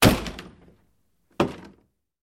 Звуки выбивания двери
Громкий стук в дверь рукой